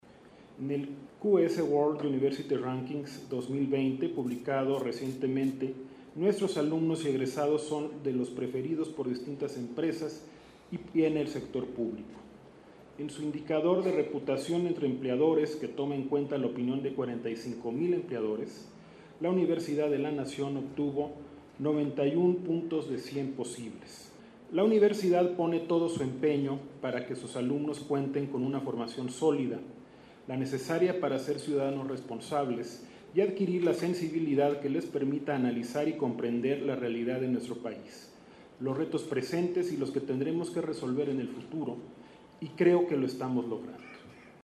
• El secretario general de la Universidad, Leonardo Lomelí, inauguró el evento en el que participan 300 empresas que ofrecen cerca de dos mil 500 empleos